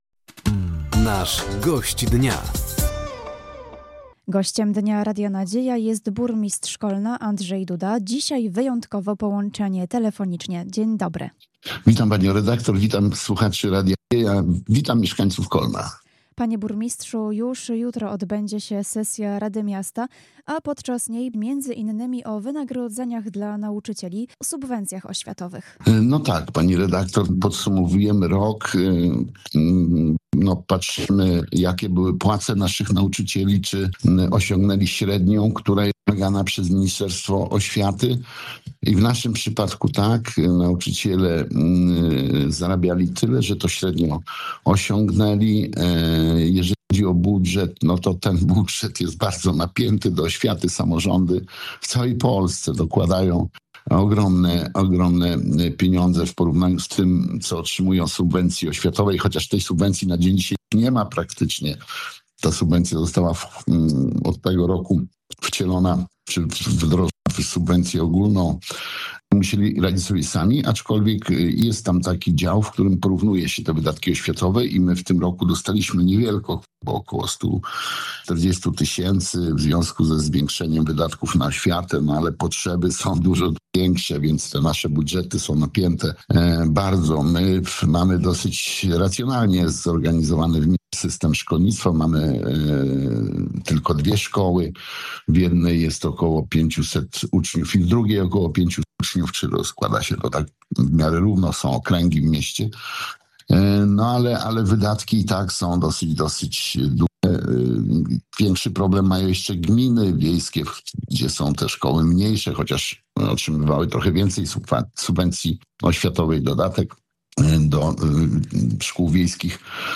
Gościem Dnia Radia Nadzieja był burmistrz Kolna Andrzej Duda. Tematem rozmowy była między innymi jutrzejsza sesja rady miasta oraz nabór do przedszkoli.